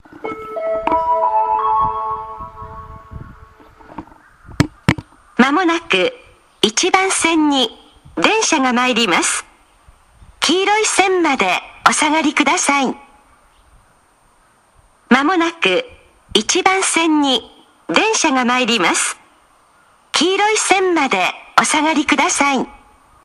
高崎問屋町駅１番線接近放送　　　高崎問屋町駅１番線接近放送です。２ターン流れています。